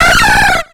Cri de Floravol dans Pokémon X et Y.